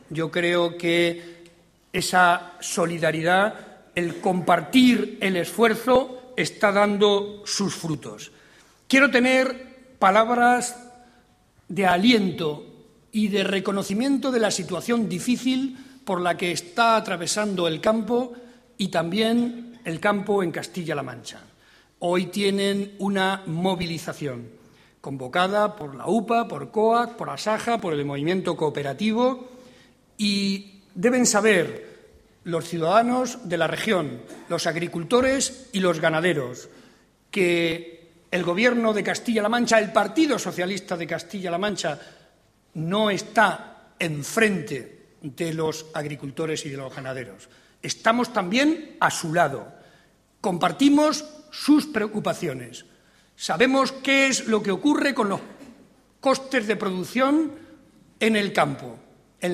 Barreda hizo estas declaraciones durante su intervención en el Comité regional del PSCM-PSOE, en Toledo.
Corte sonoro Barreda Comité Regional